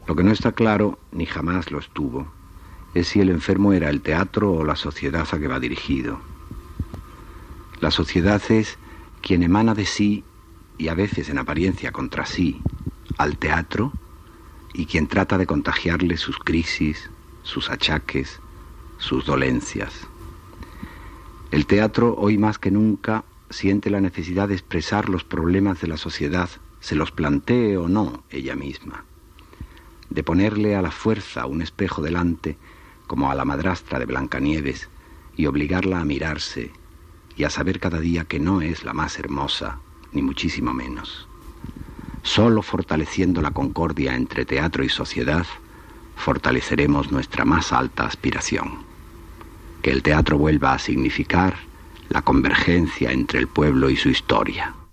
Escrit d'Antonio Gala, llegit per ell mateix", amb motiu del Dia Mundial del Teatre convocat per la UNESCO